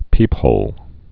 (pēphōl)